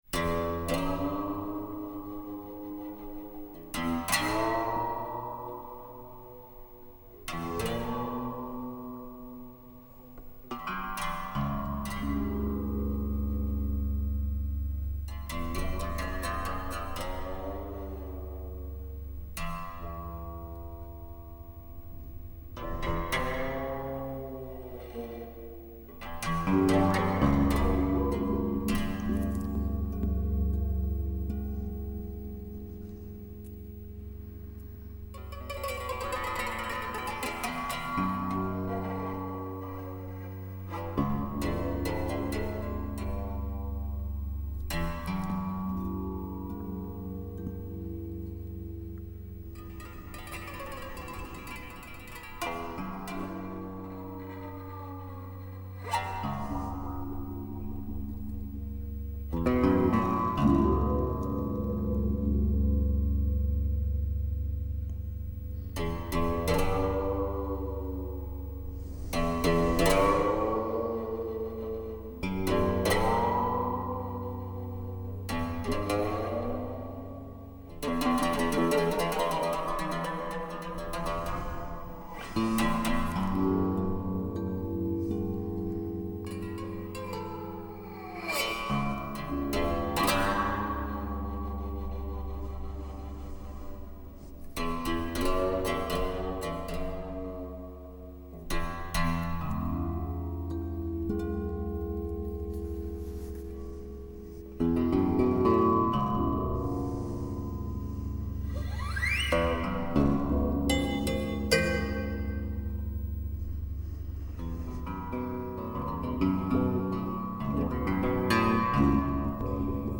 A lush musical painting of the Australian landscape.